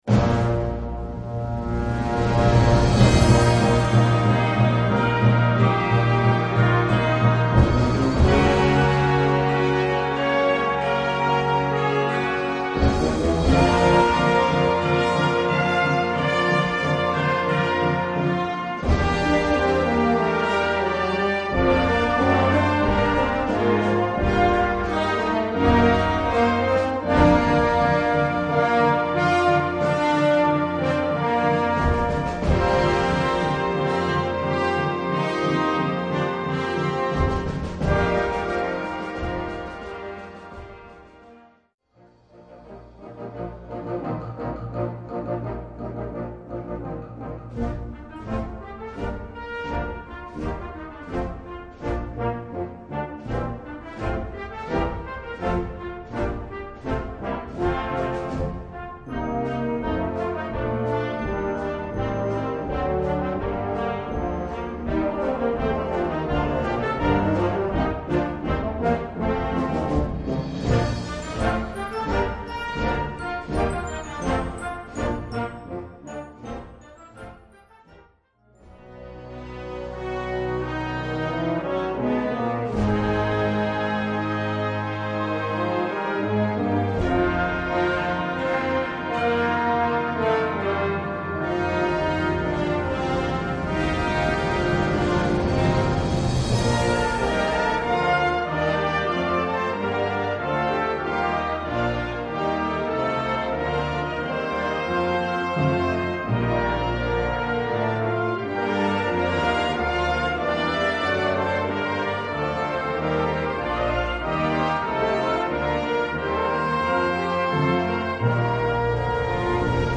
Gattung: Fantasie
Besetzung: Blasorchester